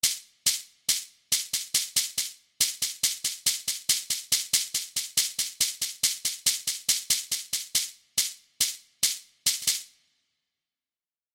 Afro-Latin American Shekere
Drumwavy-27-Afro-Latin-American-Shekere.mp3